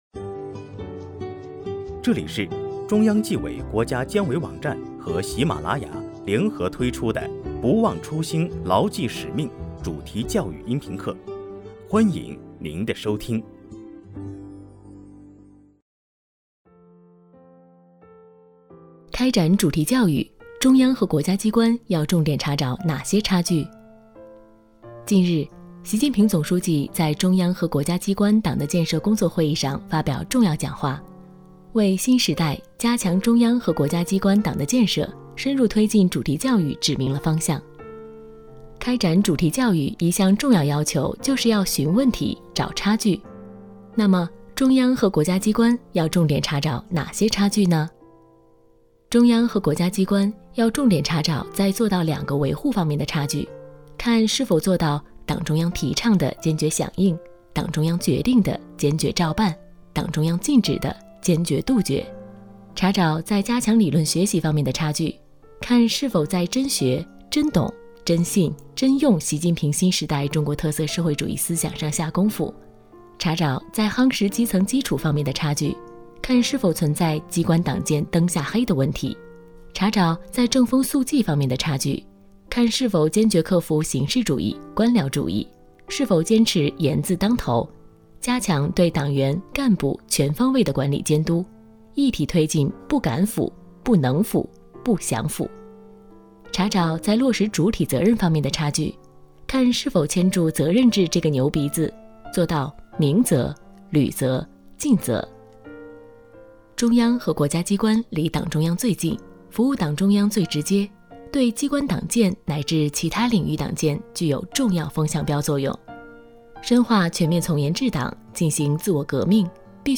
主题教育音频课（29）开展主题教育，中央和国家机关要重点查找哪些差距？